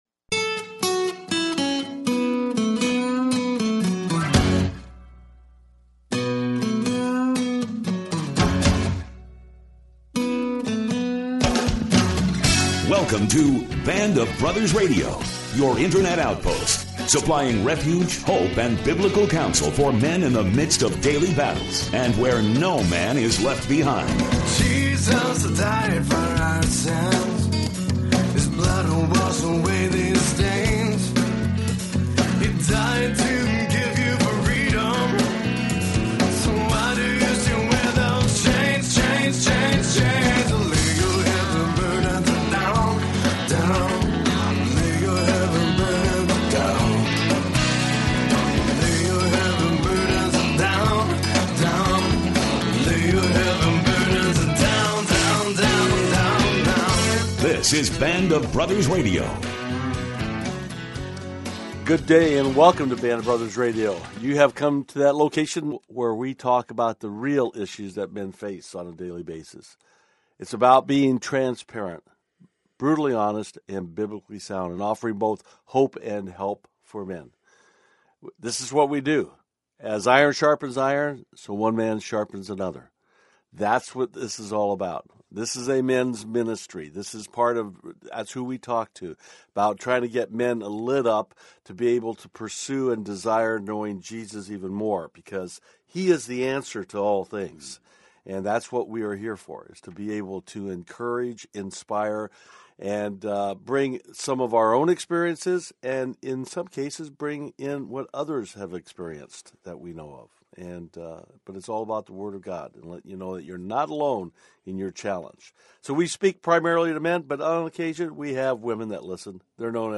The hosts answer listener’s questions on a variety of topics, including: How to deal with a critical, negative wife…and how a man can learn to control his anger